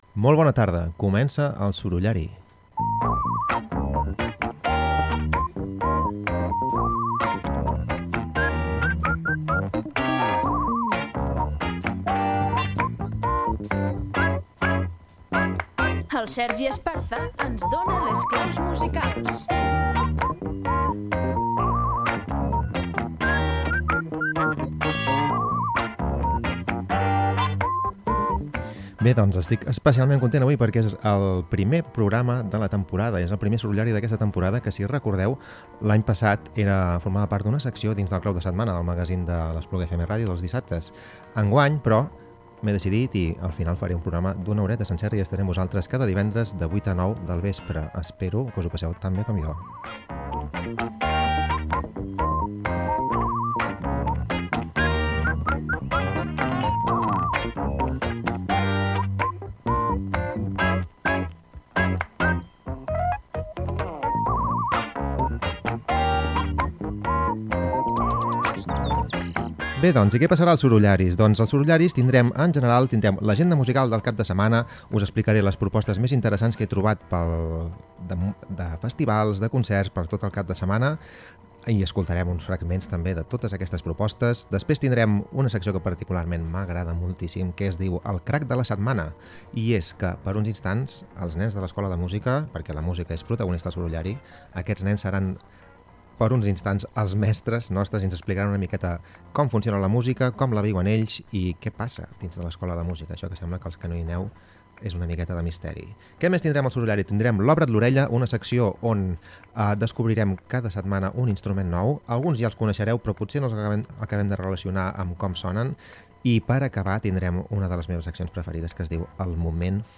En la primera edició del Sorollari es presenten les diverses seccions del programa i es destaquen les principals cites musicals previstes al territori pels pròxims dies. El programa descobreix propostes musicals i també incorpora la intervenció d’alumnes de l’Escola Municipal de Música de l’Espluga.